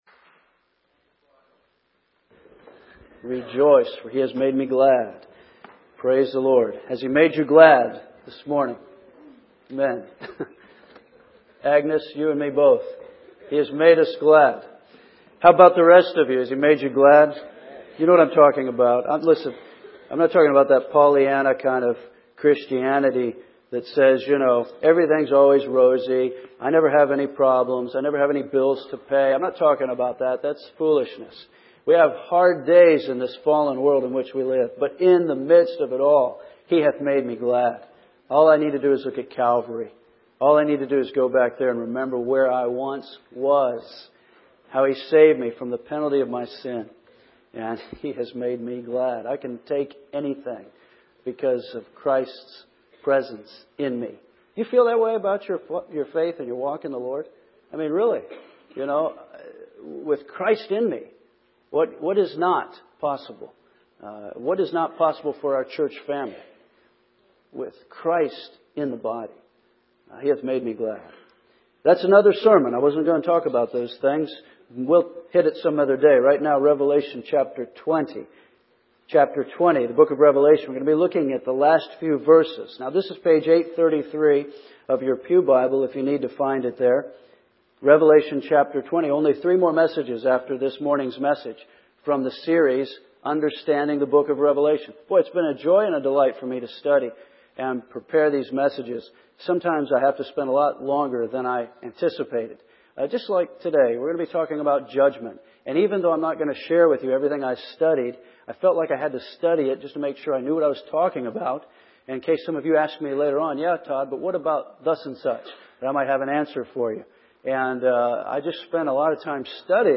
Please stand in honor of the reading of God’s Holy Word. 11 Then I saw a great white throne and Him who sat on it, from whose face the earth and the heaven fled away.